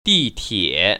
[dìtiě] 디티에  ▶